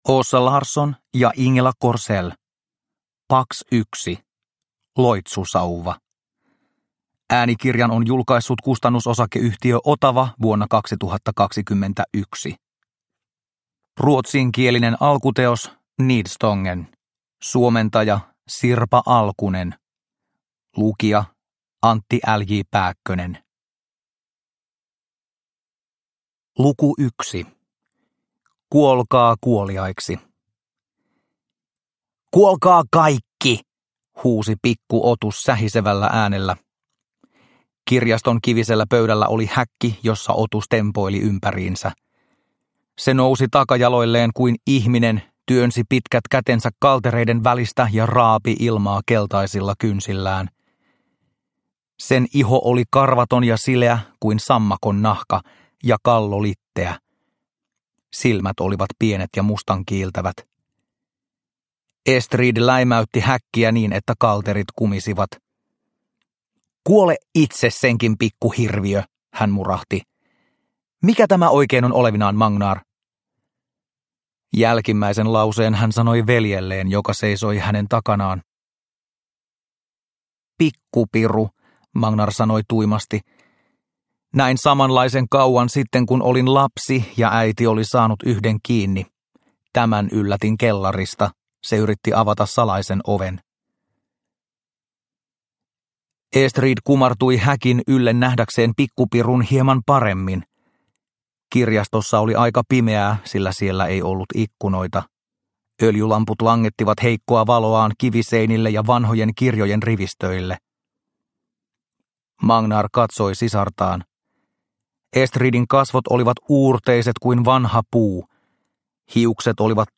Pax 1 - Loitsusauva – Ljudbok – Laddas ner